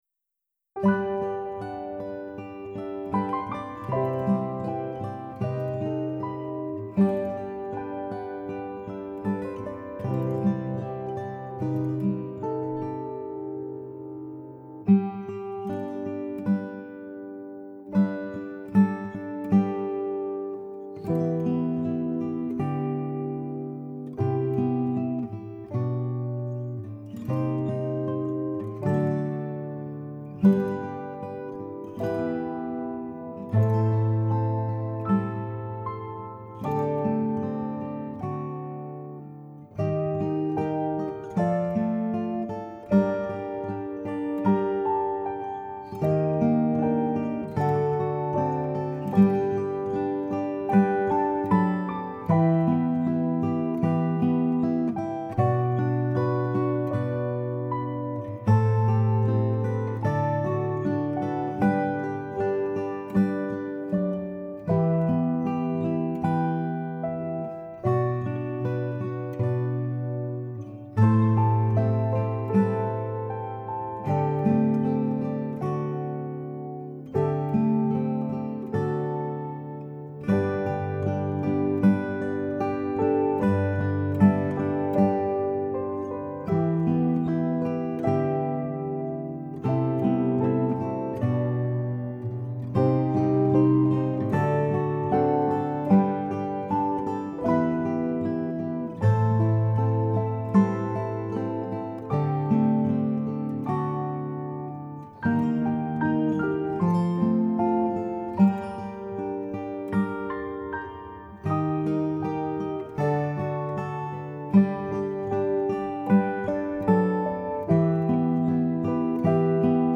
My Song Unsung Guitar & Piano 2019
my-song-unsung-piano-guitar-7-16-19.mp3